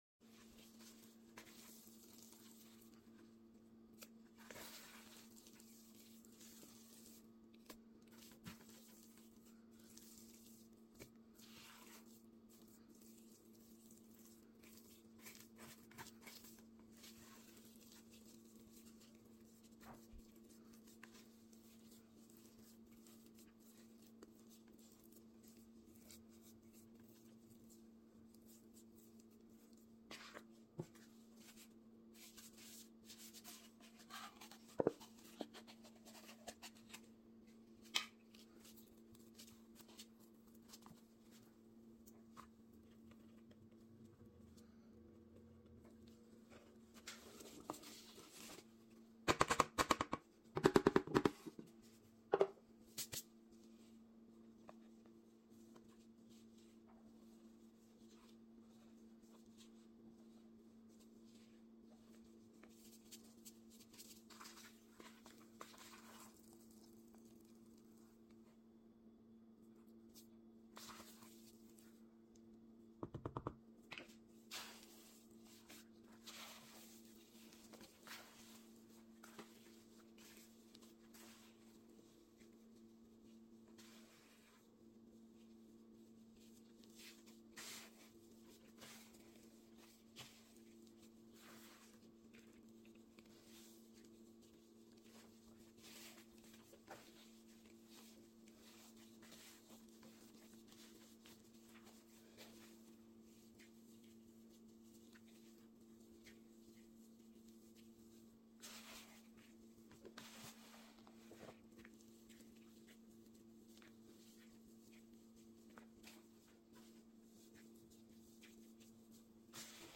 making cylinder of drying powder